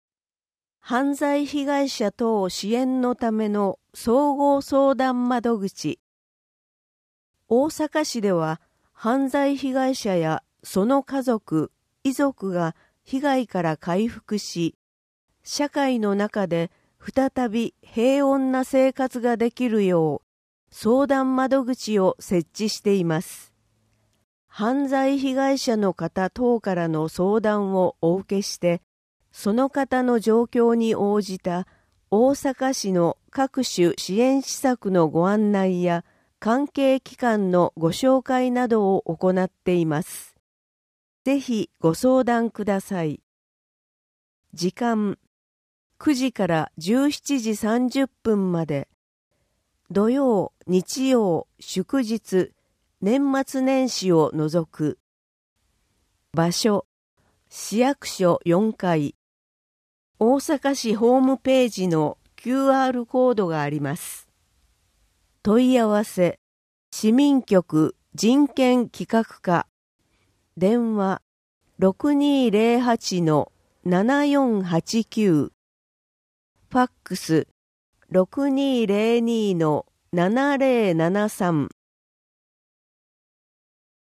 音訳版「よどマガ！」（令和7年1月号）